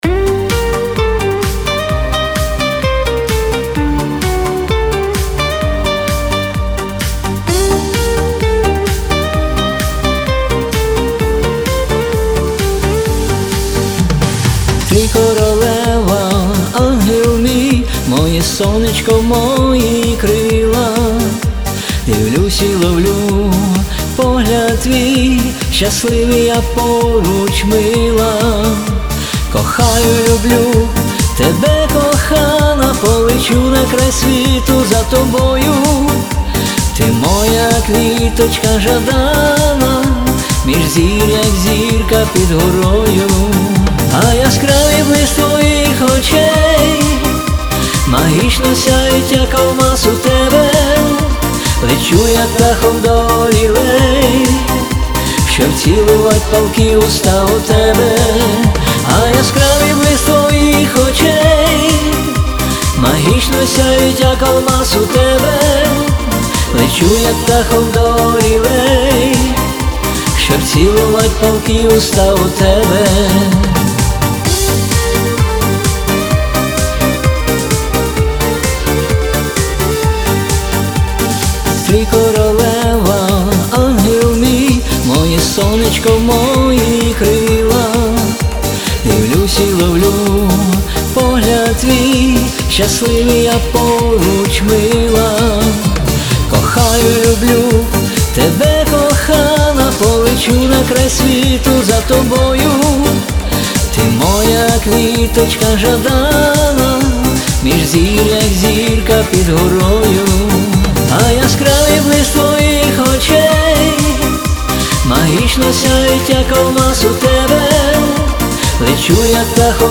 СТИЛЬОВІ ЖАНРИ: Ліричний